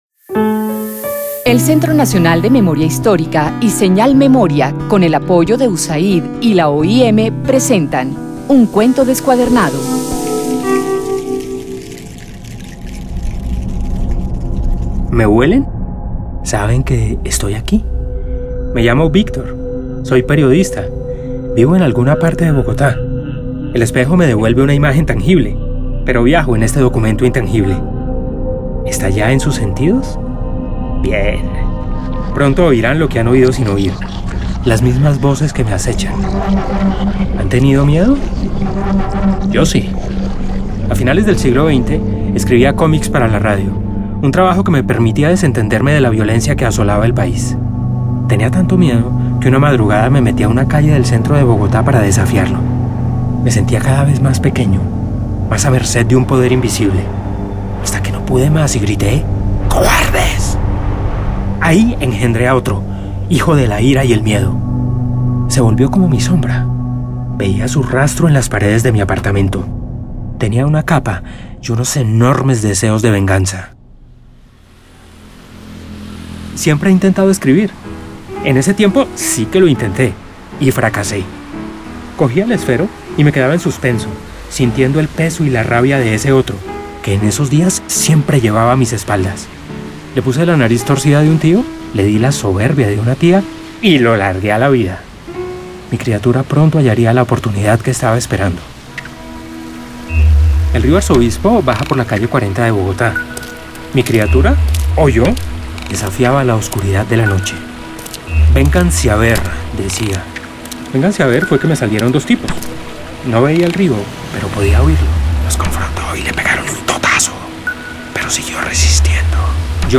Narrativas literarias
Cuentos, cuñas, programas. Serie Radial ¡Basta Ya! La Vida Cuenta.